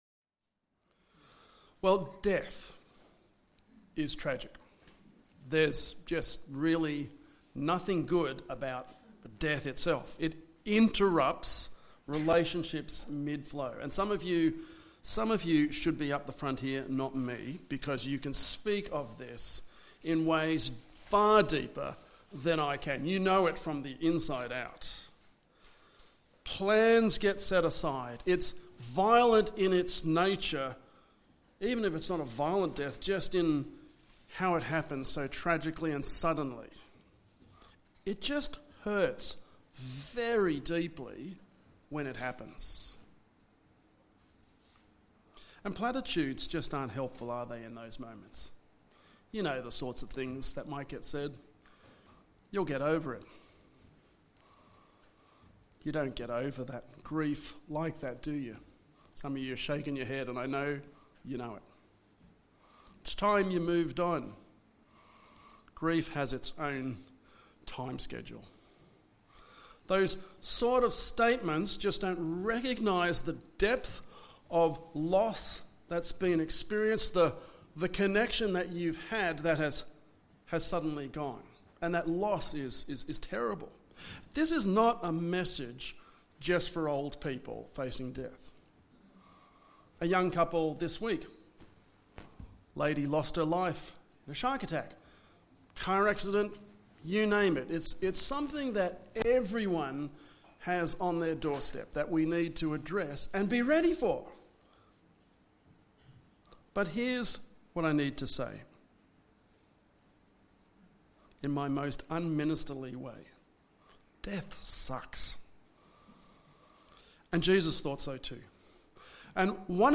Western Blacktown Presbyterian Church is now Hope at the Hill meeting at Rooty Hill.
Sermon